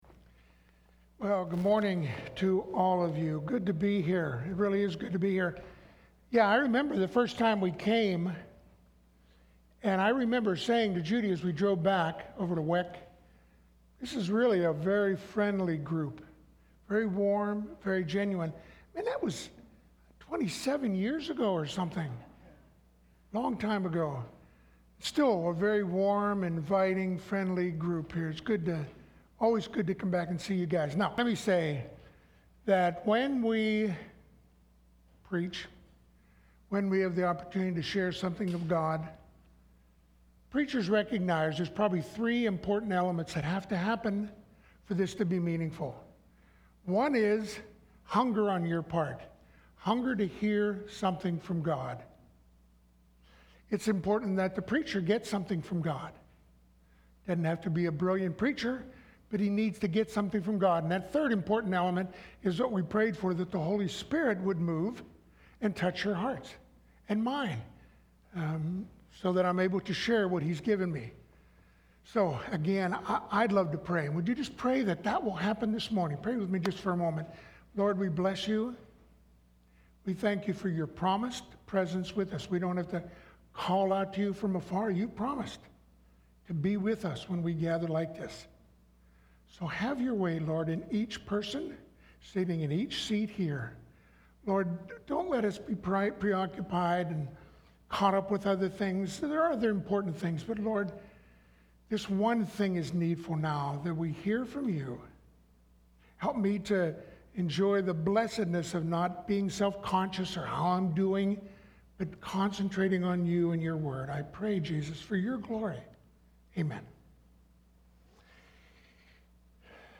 Occasion: Mission Sunday